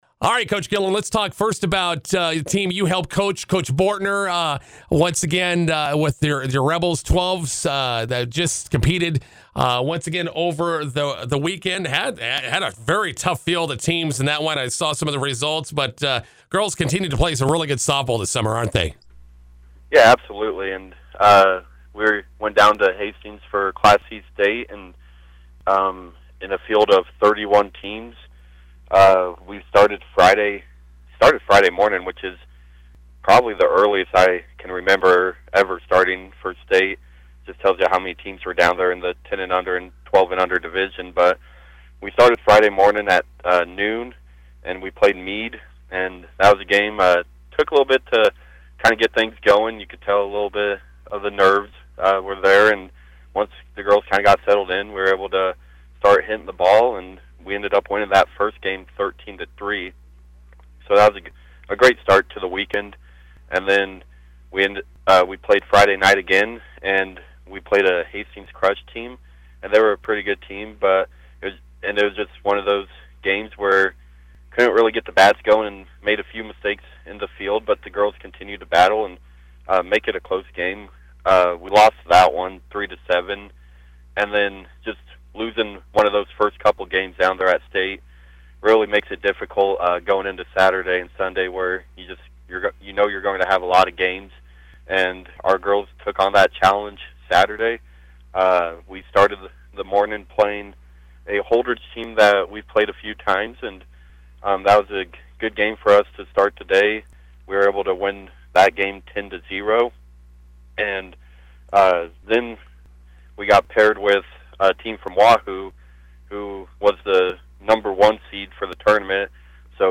INTERVIEW: McCook Rebels Firecracker Softball Tournament coming up this weekend.